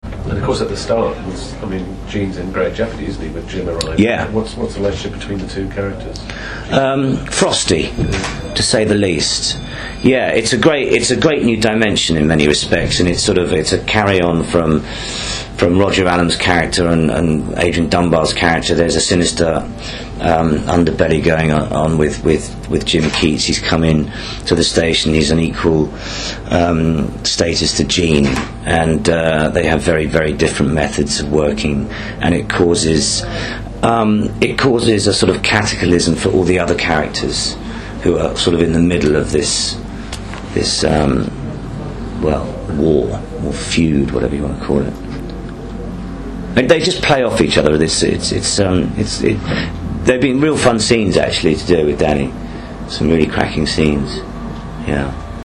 So here are a few I’ve edited from my interview with Phil and saved in MP3 format.
As you may have read in the feature, our final series conversation took place in his dressing room on set in Bermondsey just days before filming ended in February.